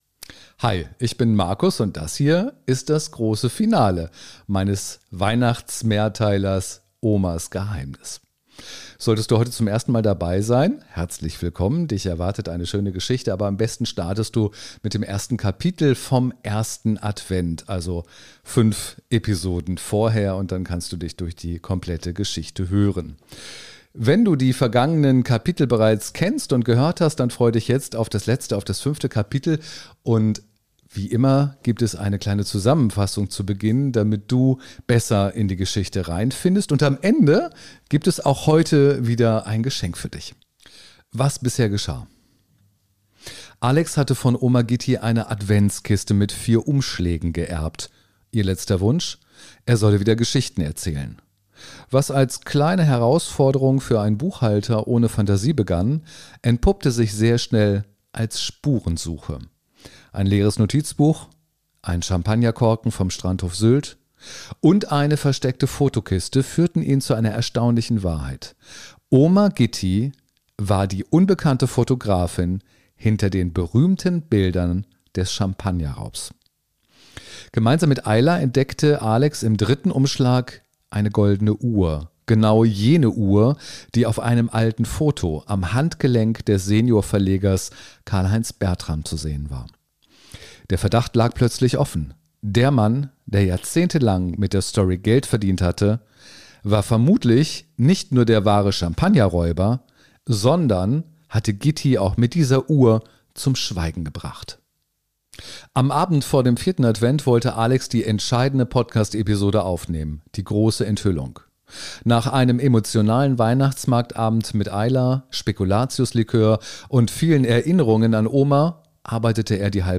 Warmherzig, atmosphärisch und geheimnisvoll erzählt – ideal für eine Tasse Tee, ein Stück Rotweinkuchen und ein bisschen Adventsmagie.